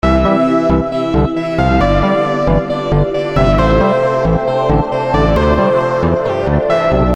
Snyth Trance Bass 02
描述：合成低音
Tag: 140 bpm Trance Loops Bass Loops 1.15 MB wav Key : Unknown